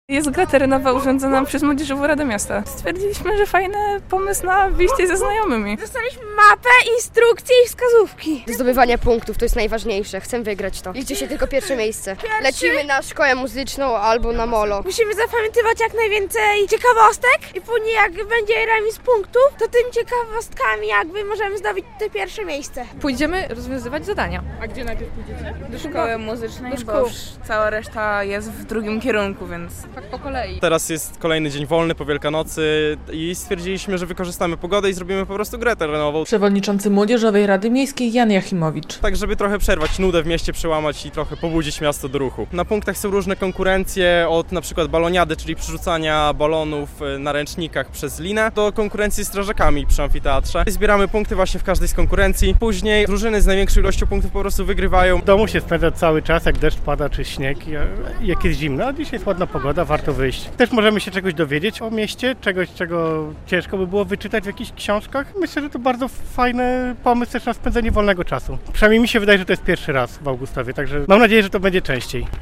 Wielkanocna gra terenowa w Augustowie - relacja